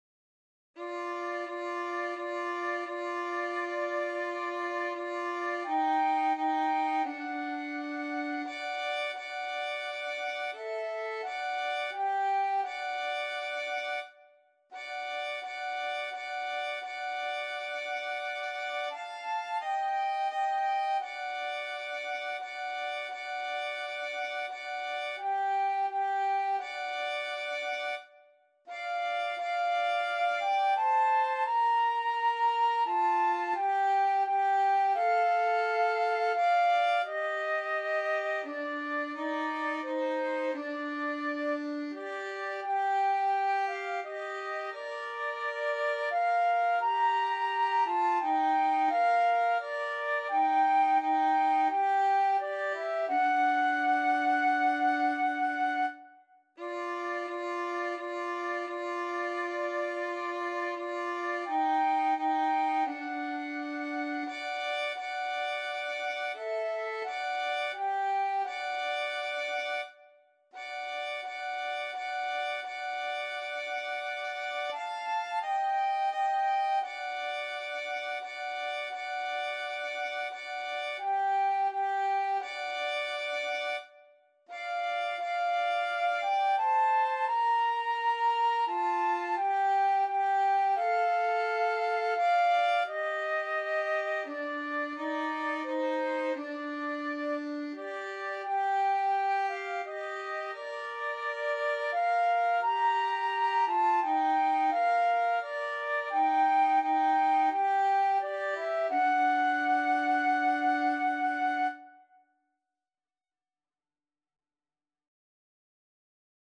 FluteViolin
4/4 (View more 4/4 Music)
Andante =c.86